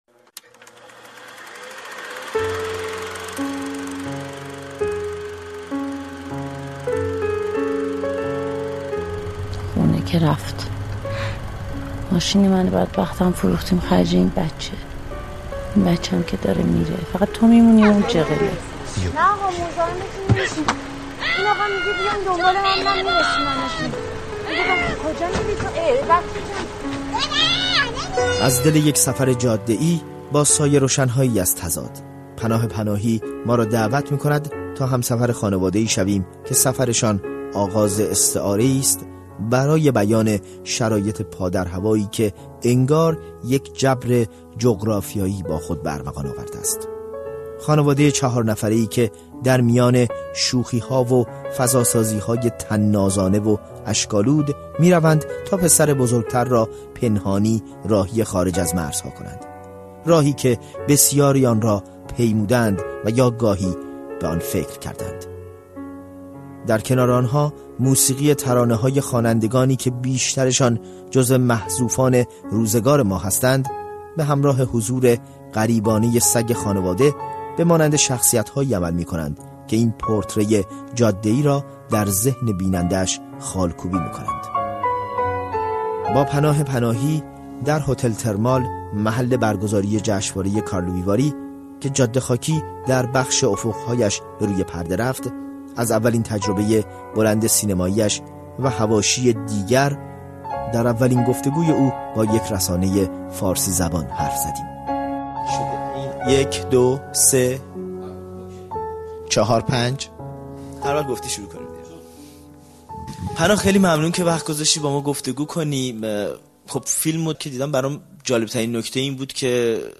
با پناه پناهی در هتل محل برگزاری جشنواره کارلووی‌واری که فیلم «جاده خاکی» او نیز در آن اکران شد، حرف زدیم و او در اولین مصاحبه با یک رسانۀ فارسی‌زبان از اولین تجربۀ سینمایی‌اش گفت.